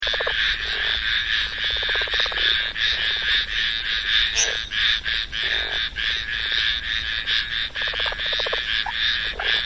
Rana berlandieri
Click here to hear the call of this species (152 KB MP3 file)
R_berlandieri.mp3